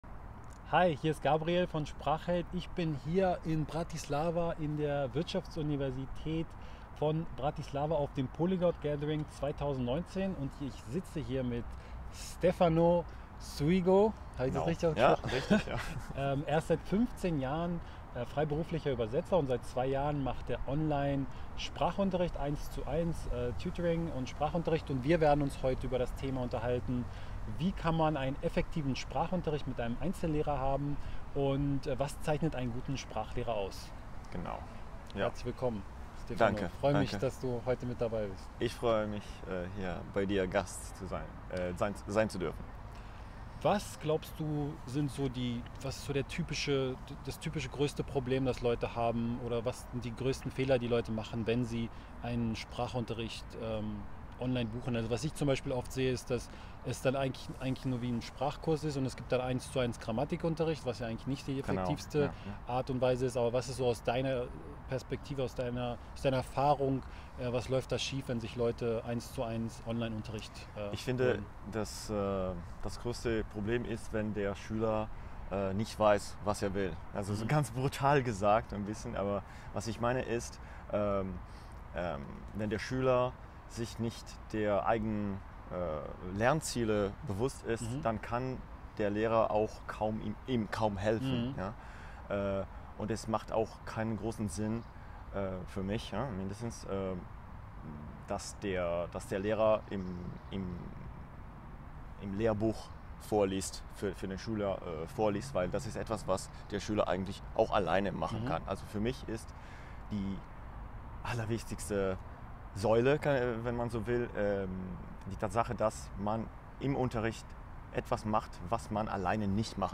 Wie findest Du einen guten Sprachlehrer (online)? Interview